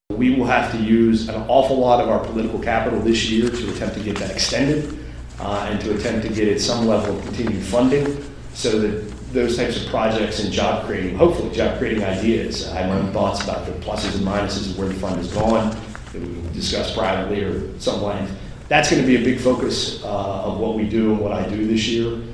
Funding for the economic initiative was established in 2022, but runs out at the end of fiscal year 2025.  In light of the state’s budget crisis, targeted focus on legislation to keep that funding coming is crucial, according to Maryland House Minority Leader Jason Buckel…